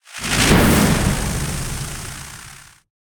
spell-impact-lightning-1.ogg